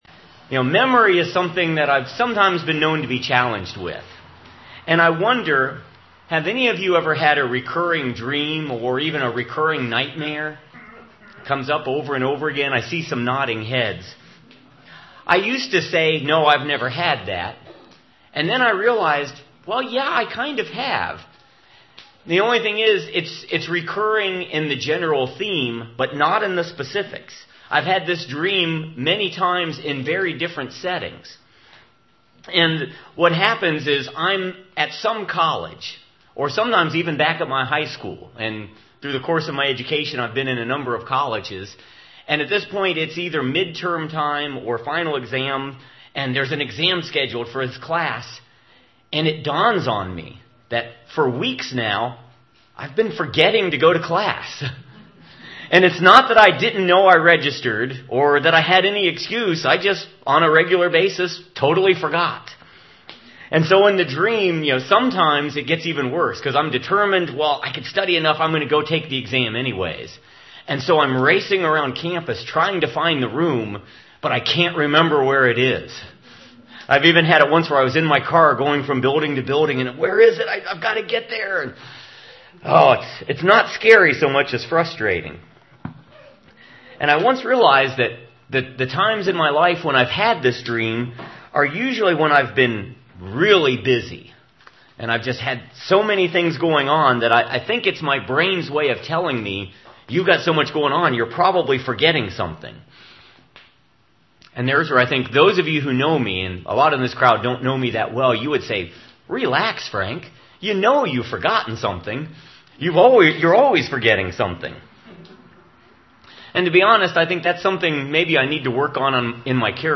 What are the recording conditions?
This sermon is from Cincinnati North's fall outdoor service.